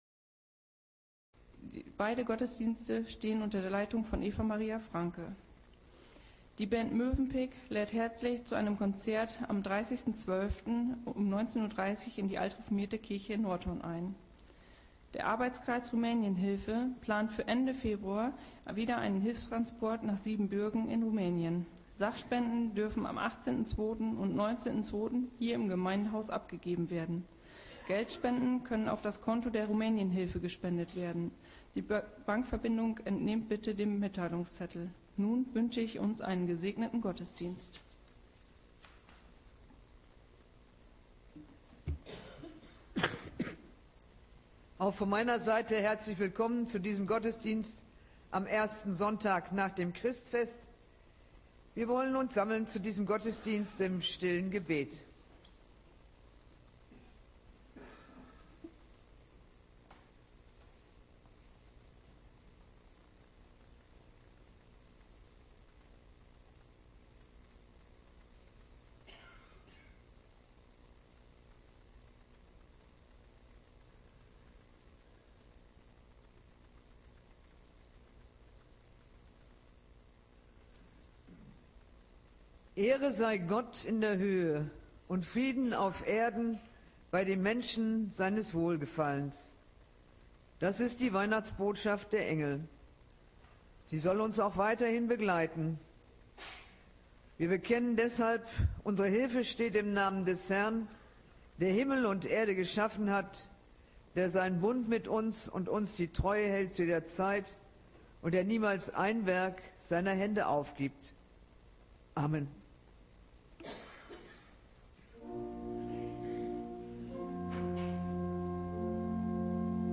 Gottesdienst Sonntag 28.12.2025 | Evangelisch-altreformierte Kirchengemeinde Laar
Wir laden ein, folgende Lieder aus dem Evangelischen Gesangbuch und dem Liederheft mitzusingen: Lied 177, 2, Lied 542, 1 – 5, Lied 39, 1 – 4, Psalm 98, 1 – 4, Lied 544, 1 – 5, Lied 44, 1 – 3